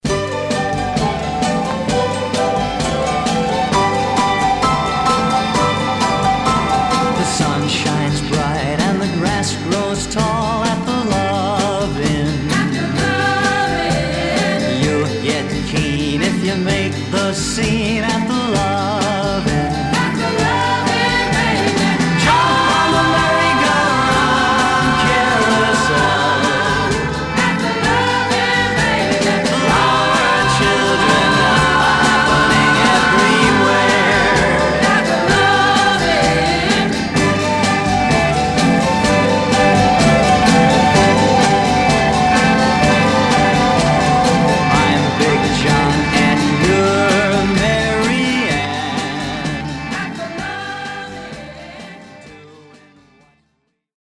Some surface noise/wear
Mono
Teen